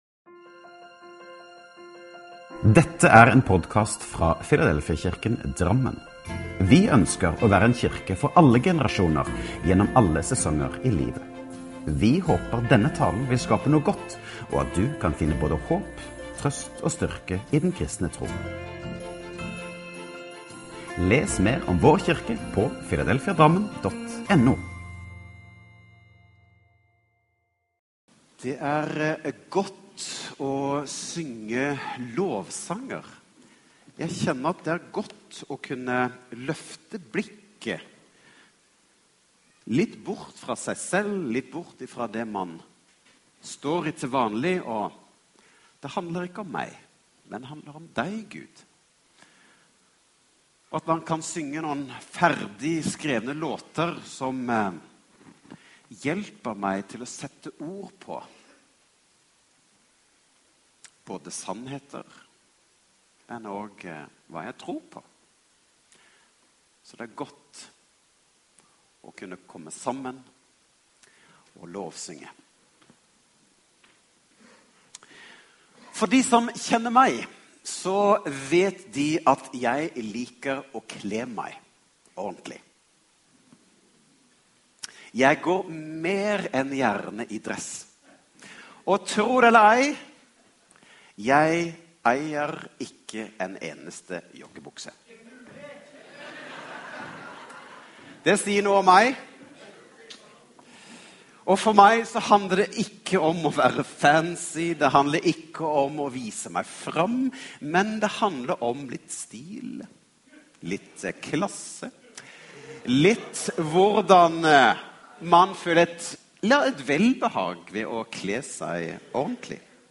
Last ned talen til egen maskin eller spill den av direkte: